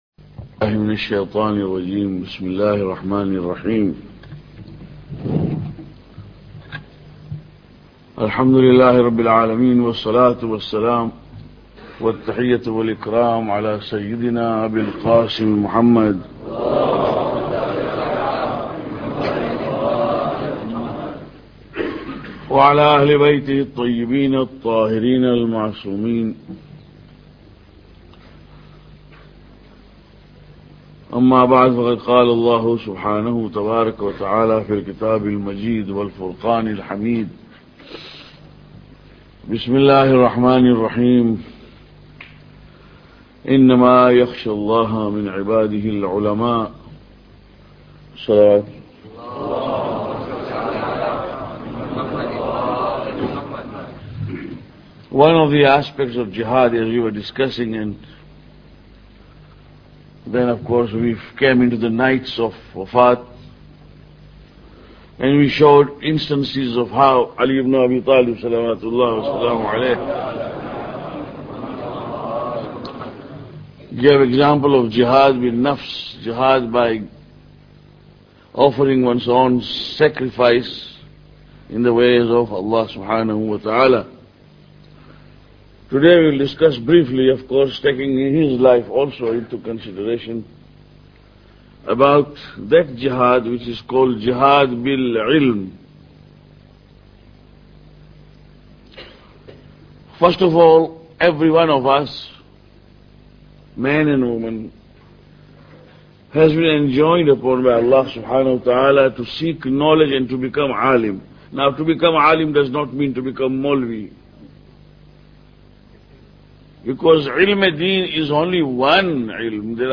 Lecture 19